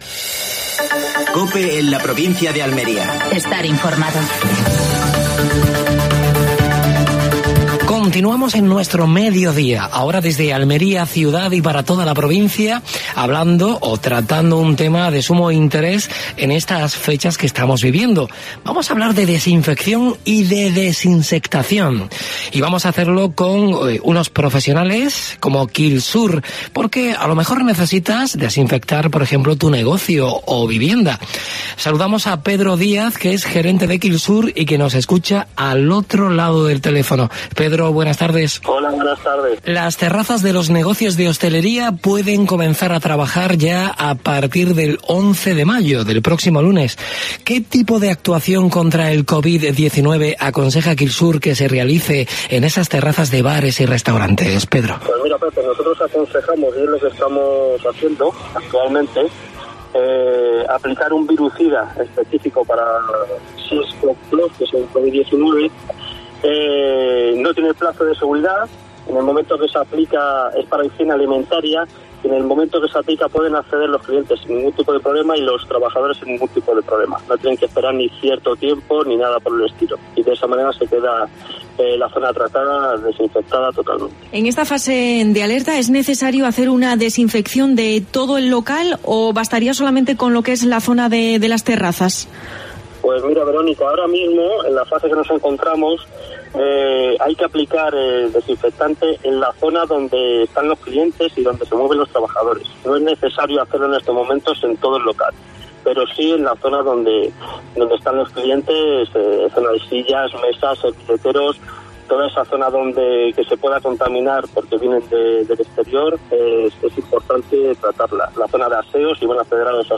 AUDIO: Actualidad en la provincia de Almería. Entrevista